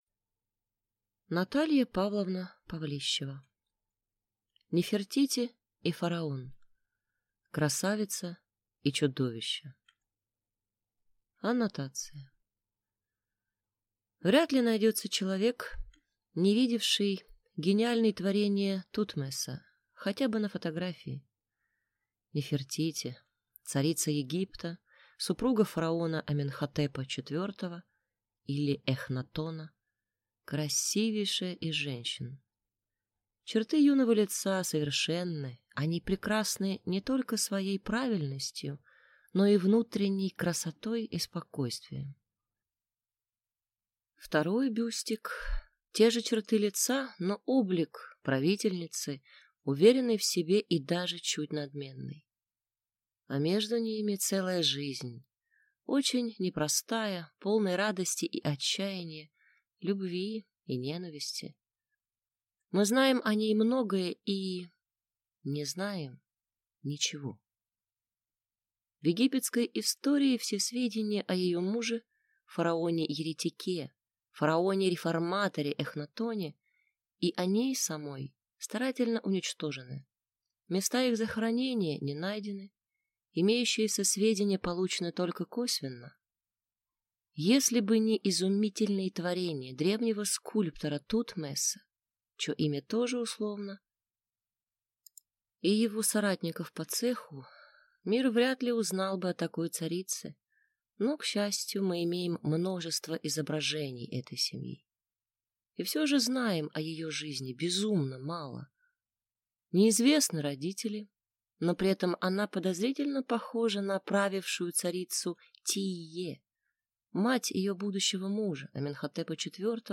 Аудиокнига Нефертити и фараон. Красавица и чудовище | Библиотека аудиокниг